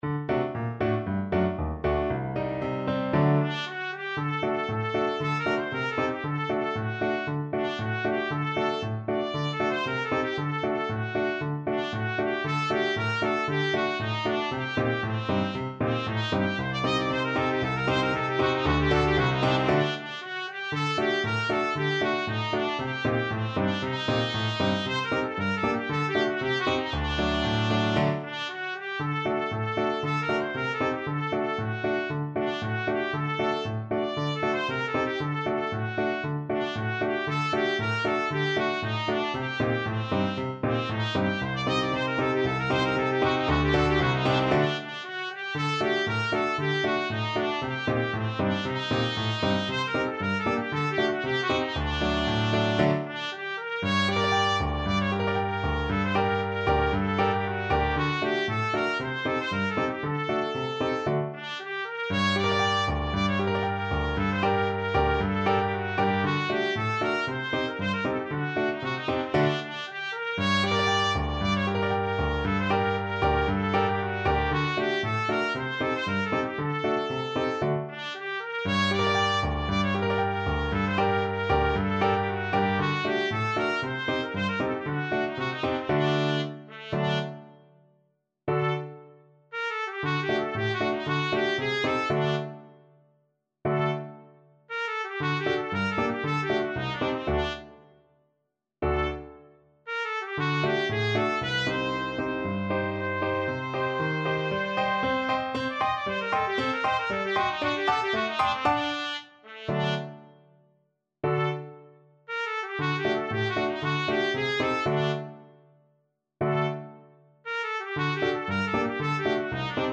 Trumpet
G minor (Sounding Pitch) A minor (Trumpet in Bb) (View more G minor Music for Trumpet )
2/4 (View more 2/4 Music)
Allegro =c.116 (View more music marked Allegro)
Traditional (View more Traditional Trumpet Music)
Romanian
der_heyser_bulgar_TPT.mp3